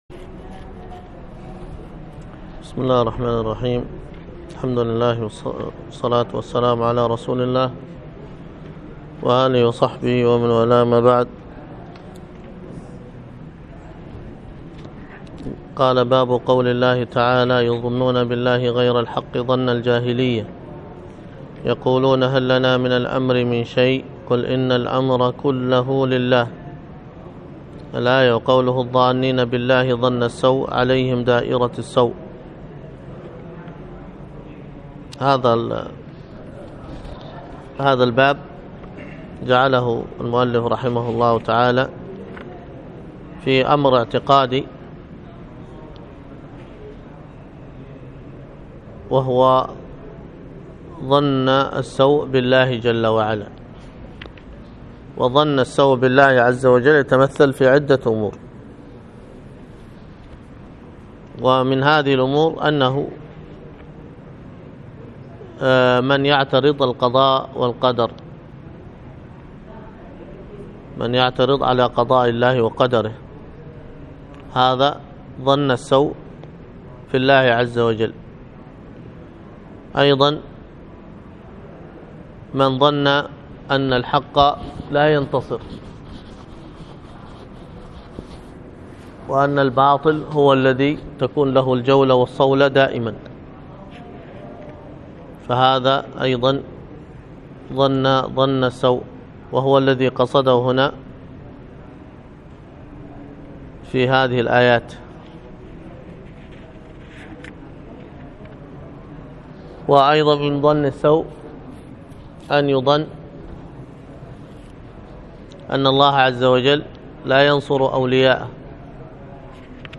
الخطبة
وكانت بمسجد التقوى بدار الحديث بالشحر